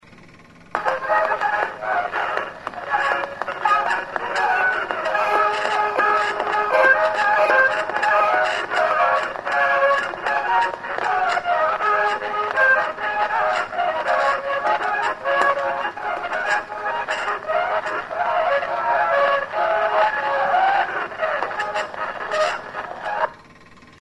EDERREGIA ZERA ZU. Fandango popular vasco. Triki-Trixa de Guipúzcoa.
Gramofonoa disko bat erreproduzitzen, 2020.
Enregistré avec cet appareil.